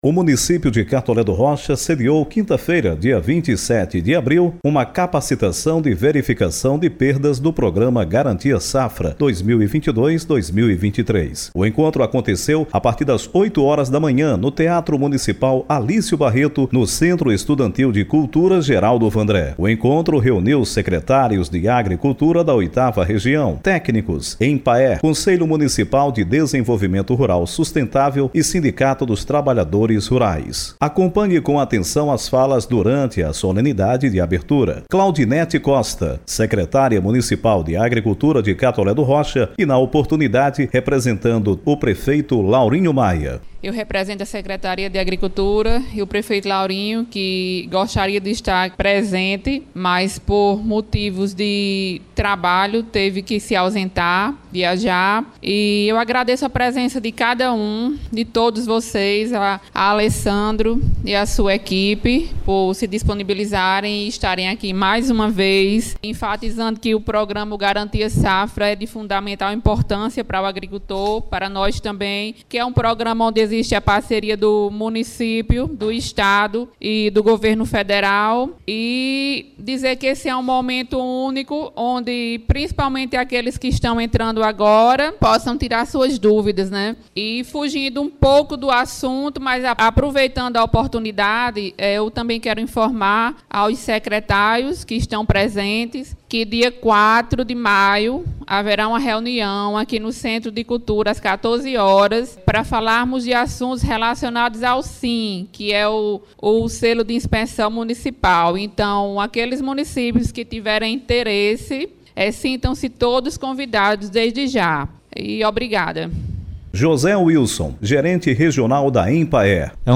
Ouça a reportagem completa sobre a Capacitação de Verificação de Perdas do Programa Garantia Safra 2022/2023.